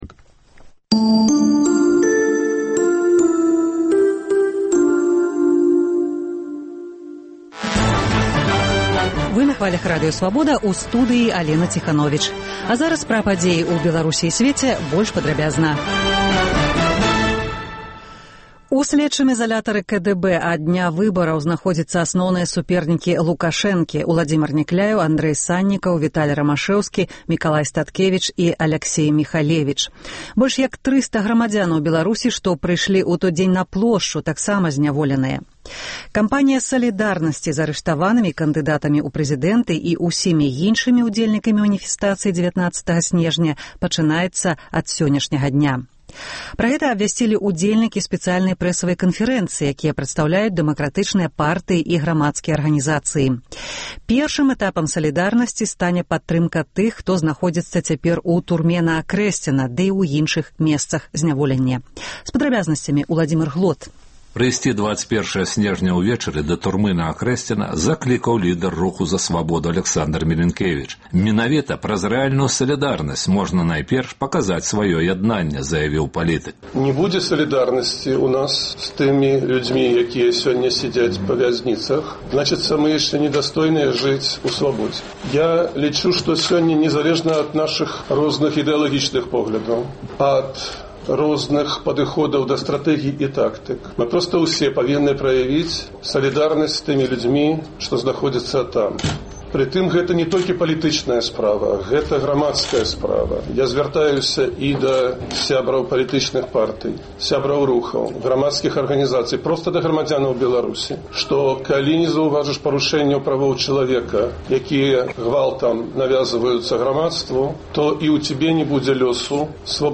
Паведамленьні нашых карэспандэнтаў, званкі слухачоў, апытаньні ў гарадах і мястэчках Беларусі. Праскі акцэнт: Які палітычны вынік, сэнс сёлетняй палітычнай кампаніі выбараў-нявыбараў? Ці чакае Беларусь новыя палітычныя замаразкі?